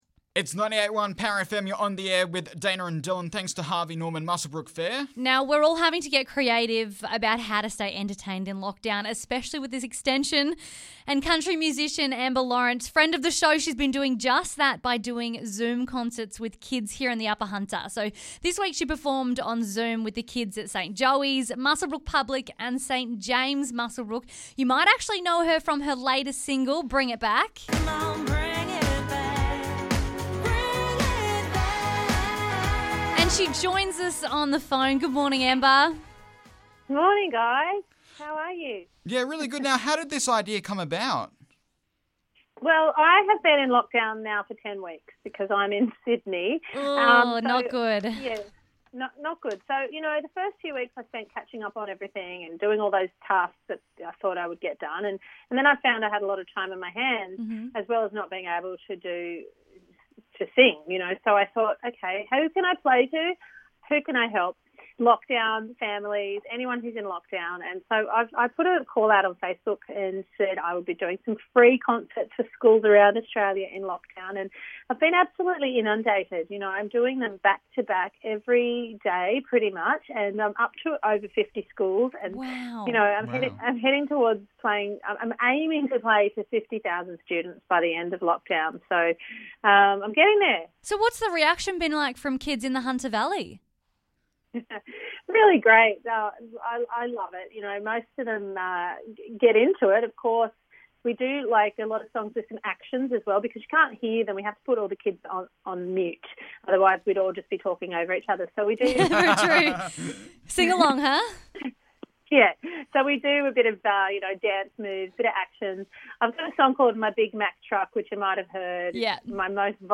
Country Star Amber Lawrence is doing virtual lockdown concerts at the moment so joined the show this morning to talk about it